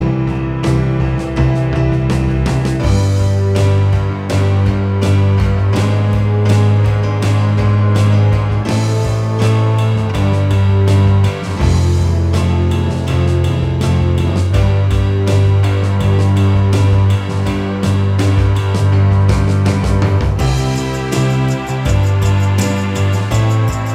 Minus Lead And Solo Indie / Alternative 4:43 Buy £1.50